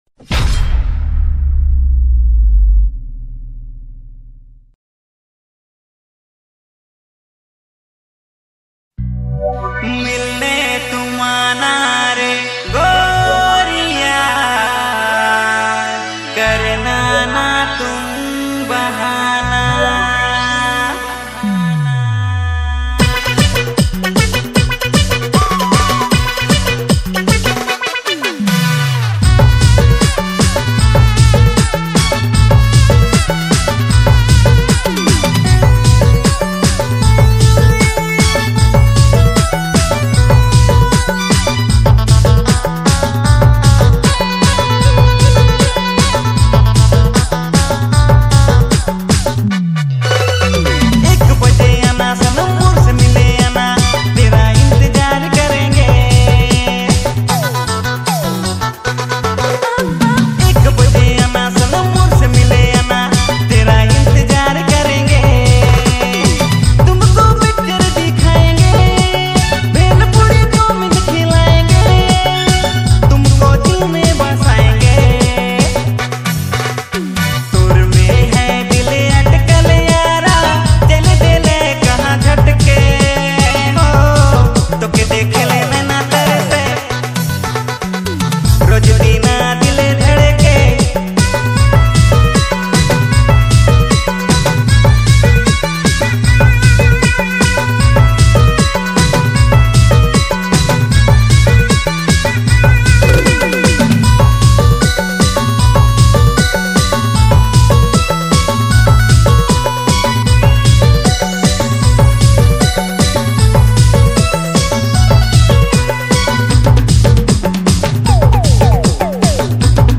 New Nagpuri Dj Songs Mp3 2025